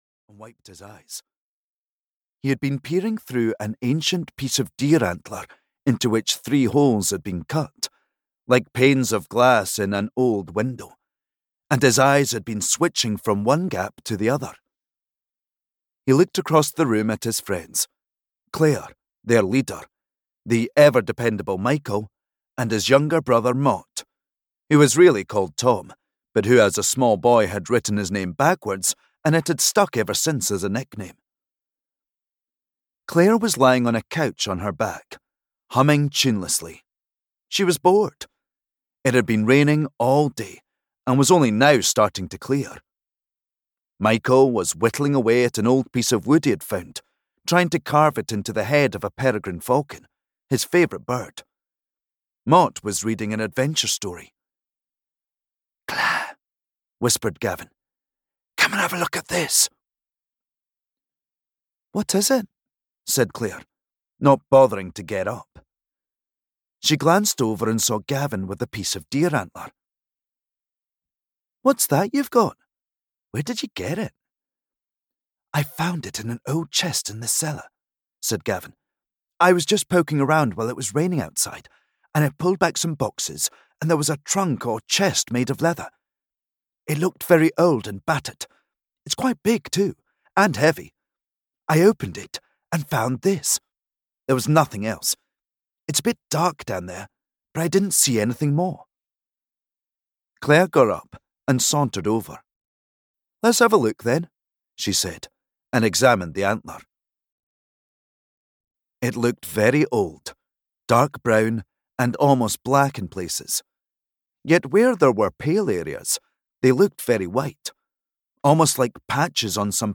The Day the Mountain Moved (EN) audiokniha
Ukázka z knihy